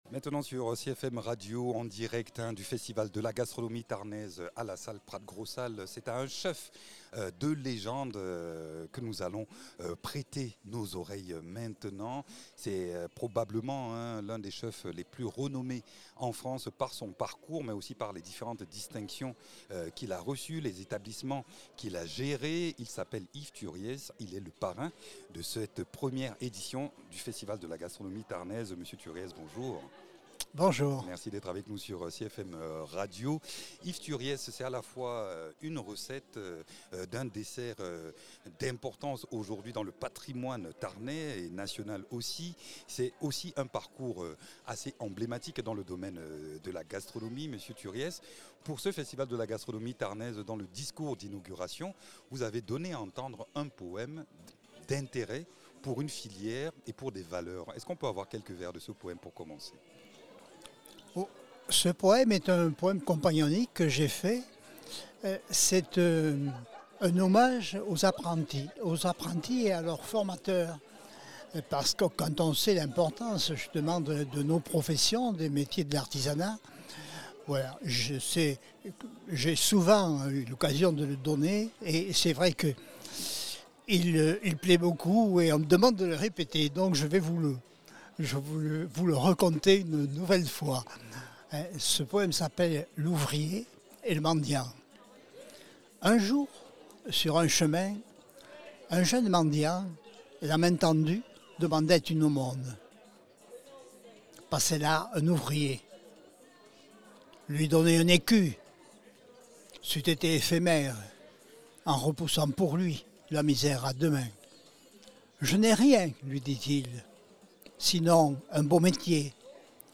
Entretien avec le parrain de ce festival de la gastronomie tarnaise.
Interviews
Invité(s) : Yves Thuries, chef restaurateur - double Meilleur Ouvrier de France.